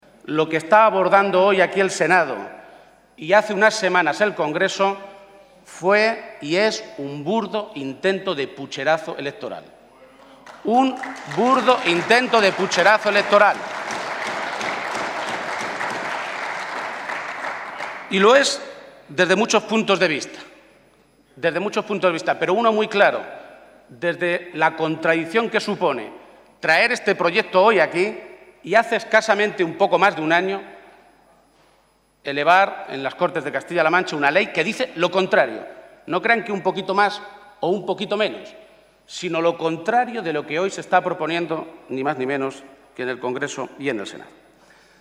Emiliano García-Page durante su intervención en el Senado
Audio Page-primera intervención Senado 2